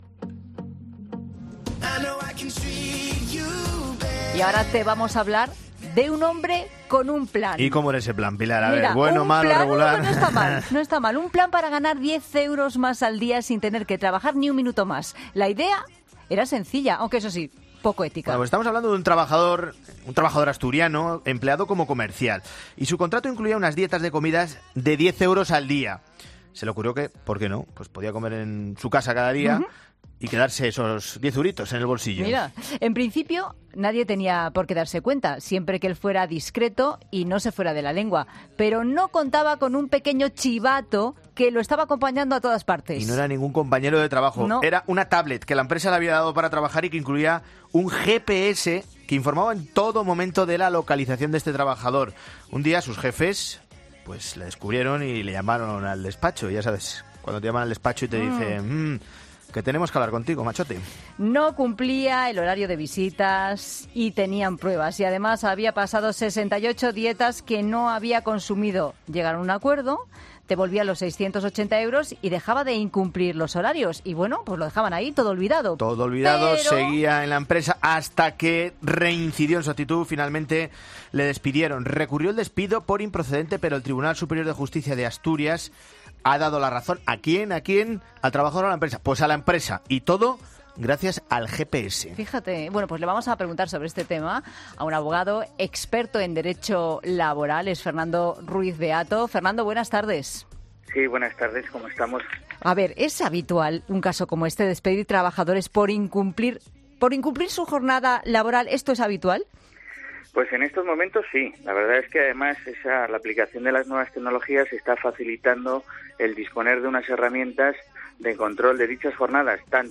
abogado, experto en Derecho Laboral